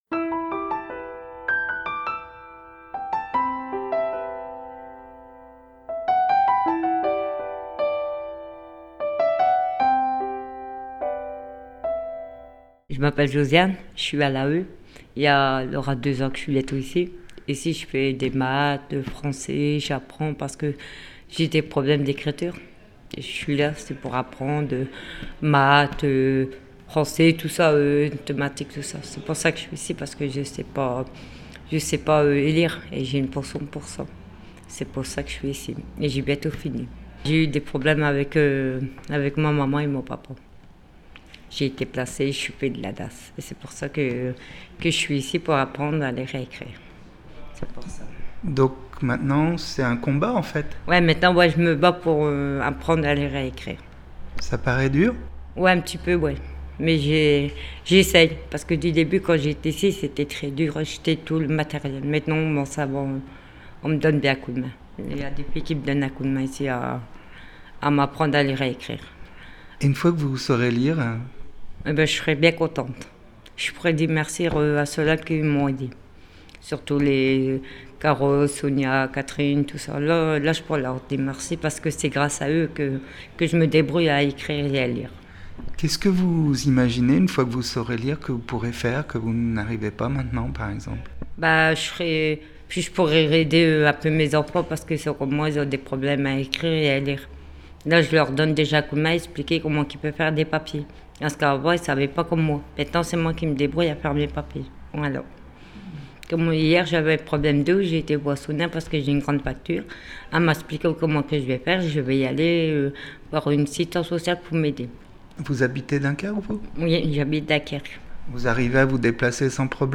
Ces portraits sonores radiophoniques sont un des volets de cette aventure artistique menée aux quatre coins du monde, la partie émergée de leur monde intérieur, une petite parcelle de ce qu'elles vivent, de ce qu'elles aiment, et de ce dont elles rêvent.